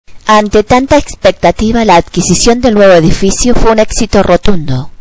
normal